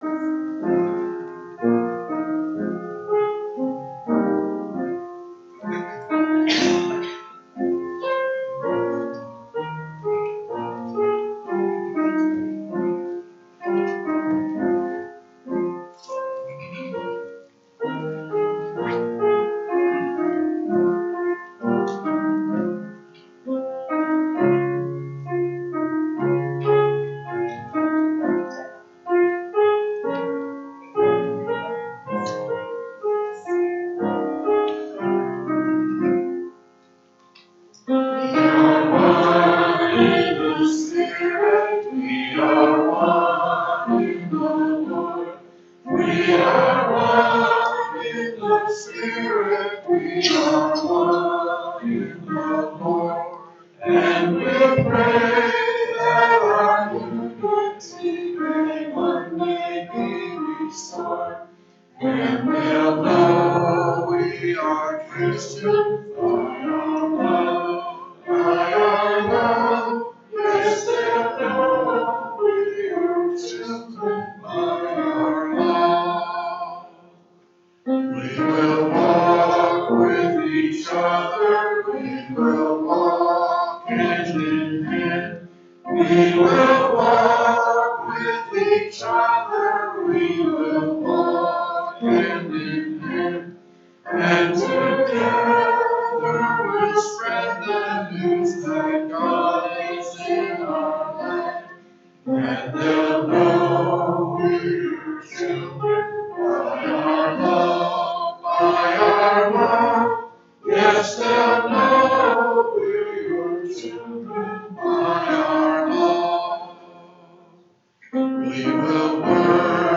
Lexington Restoration Branch - April 27, 2025 Service - Playeur